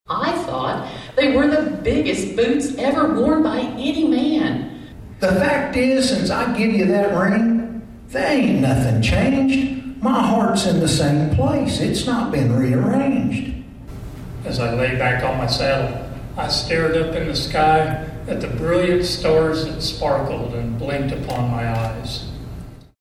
MANHATTAN — The Flint Hills Discovery Center was akin to a wild prairie Friday night for the finals of the Kansas Cowboy Poetry Contest.
Cowboy-serious-2.wav